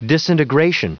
Prononciation du mot disintegration en anglais (fichier audio)
Prononciation du mot : disintegration